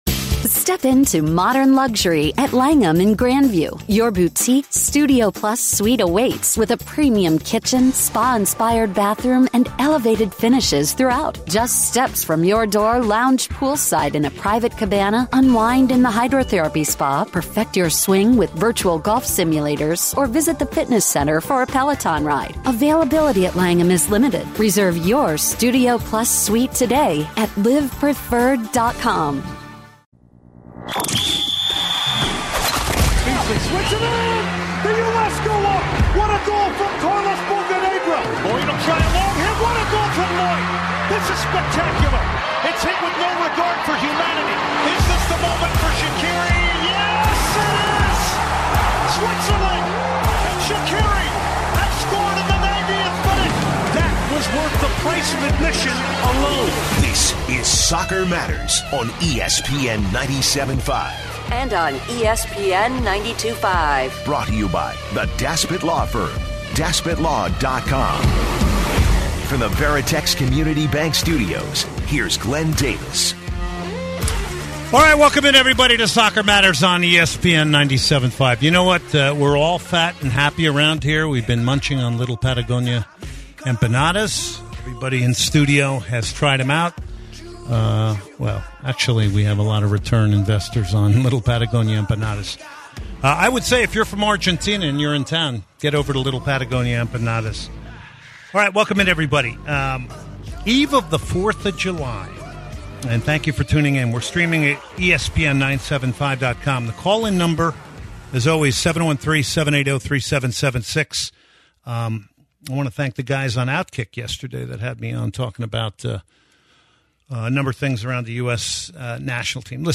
Tab Ramos joins for an interview and conversation regarding the USMNT.